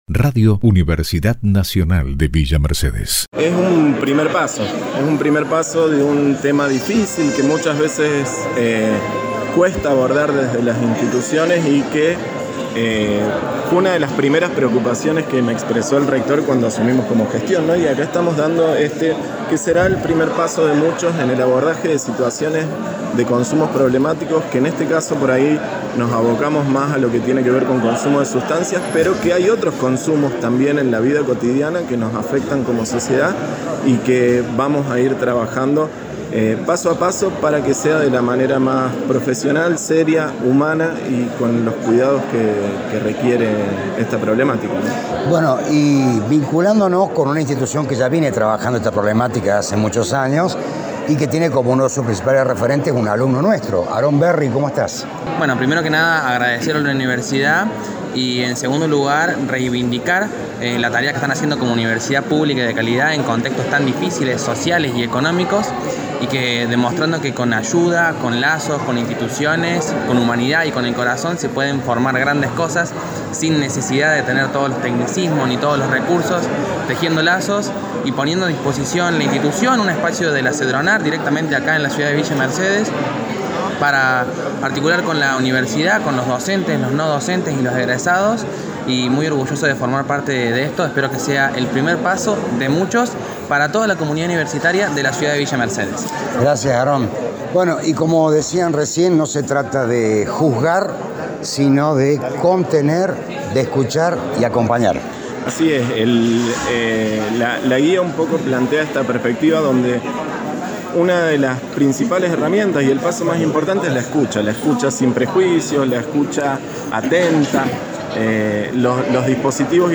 El encuentro, organizado desde la Secretaría de Asuntos Estudiantiles y Bienestar Universitario, tuvo lugar este martes en el auditorio de la Escuela Normal, y contó con una nutrida participación de miembros de la comunidad educativa y público en general.
Testimonios luego de la presentación de la Guía